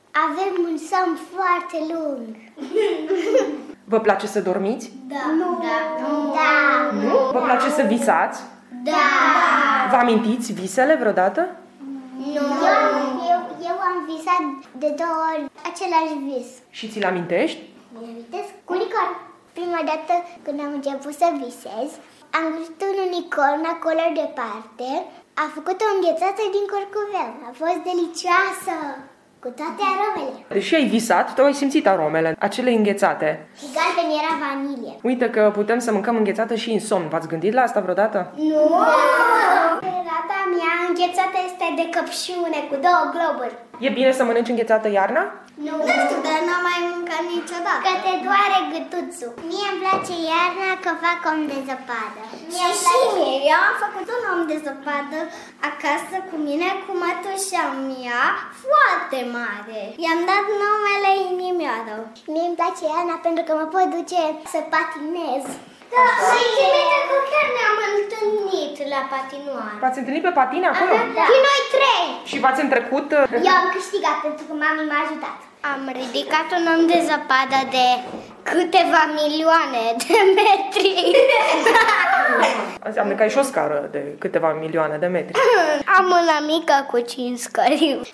Copiii visează unicorni care fac înghețată de curcubeu, clădesc oameni de zăpadă uriași, urcați pe o scară de milioane de metri și petrec ore-n șir pe patine. O fetiță ni-l prezintă pe „Inimioară”, un om de zăpadă, construit cu mătușa ei.